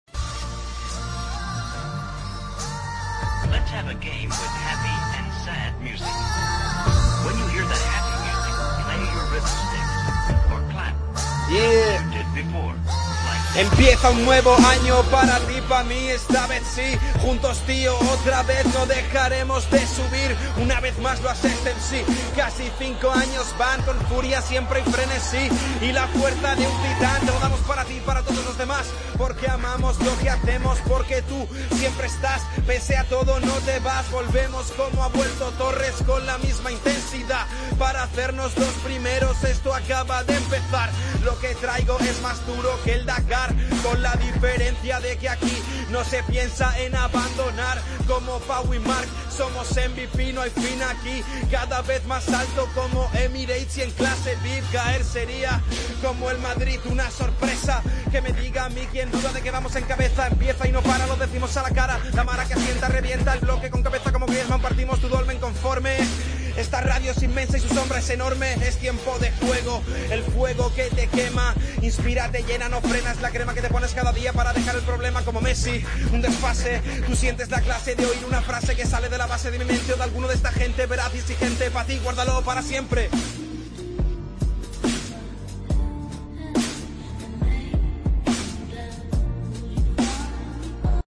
a ritmo de rap